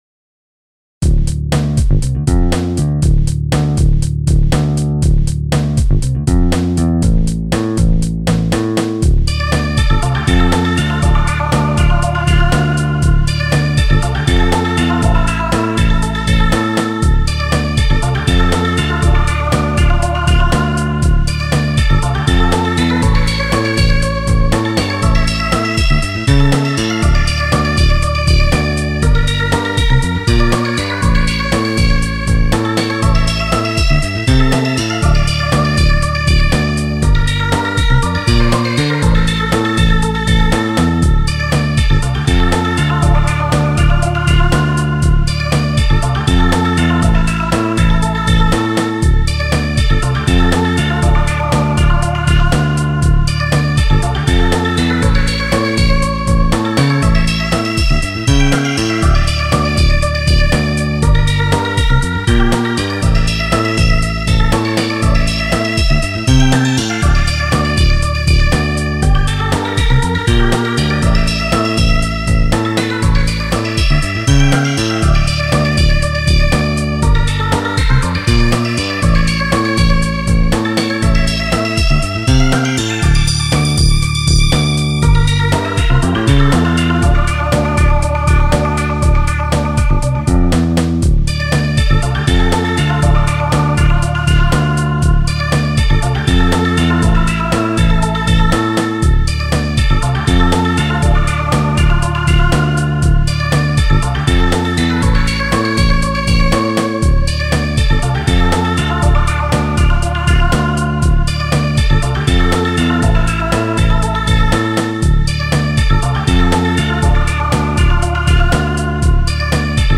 BGM
EDMロング激しい